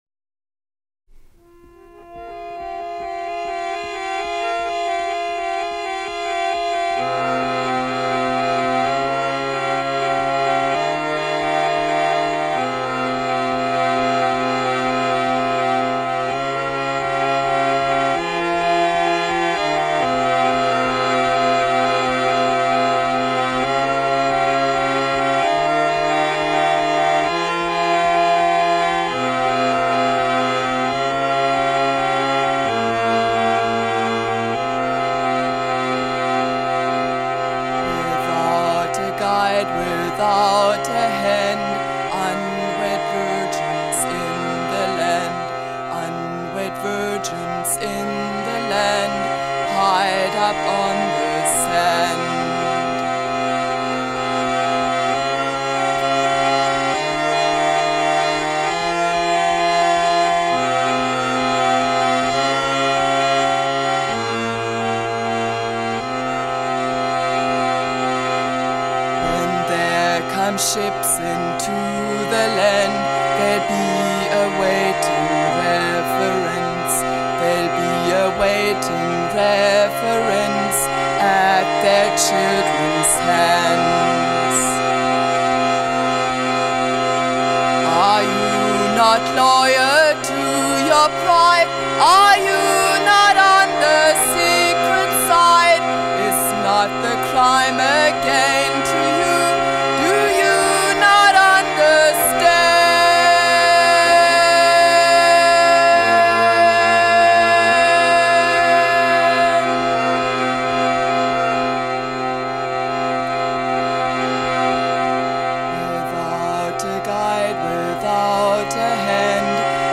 Solo voice, accompanying herself on Harmonium.